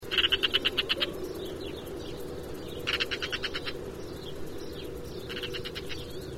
Pie-grièche à tête rousseLanius senator Passériformes
XC142141-L1  Cri d'alarme saccadé puissant : {Ex} (kè)*